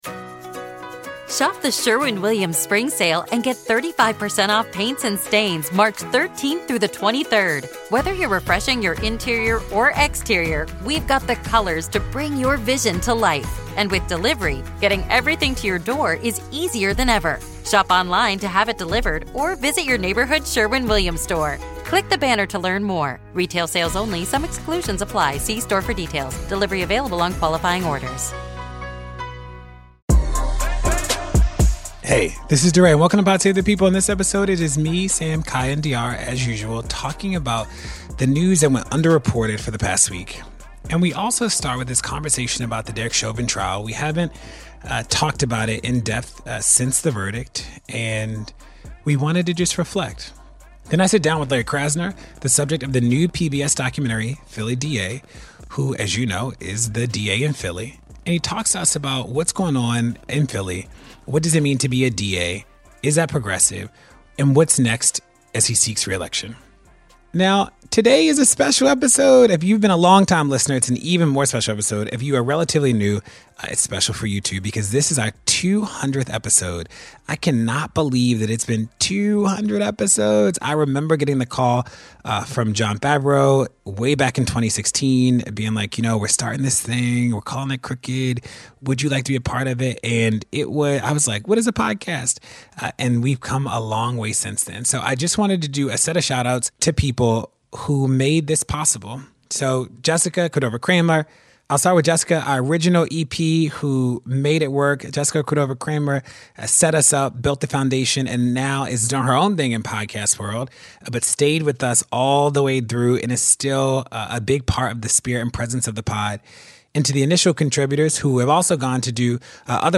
DeRay interviews Larry Krasner, the focus of a new PBS documentary "Philly D.A.", to talk about the struggles of criminal justice reform.